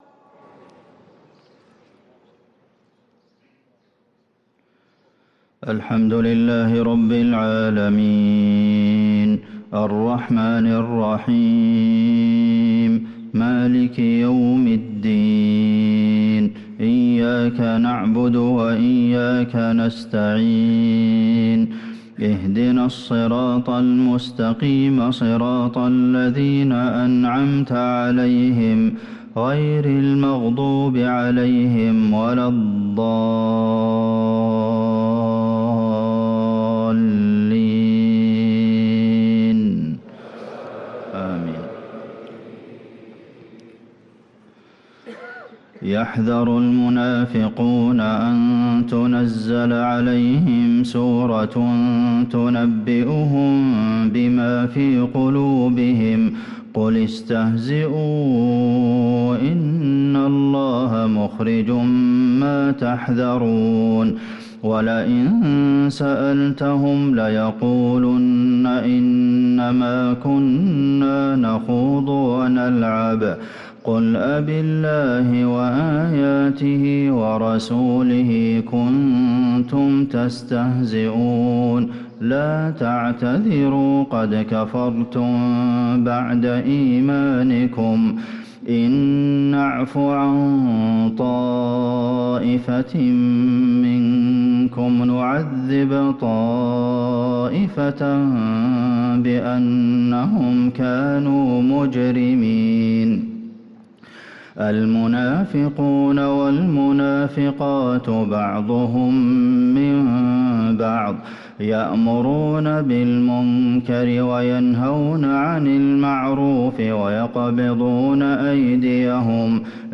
صلاة الفجر للقارئ عبدالمحسن القاسم 7 ربيع الأول 1445 هـ
تِلَاوَات الْحَرَمَيْن .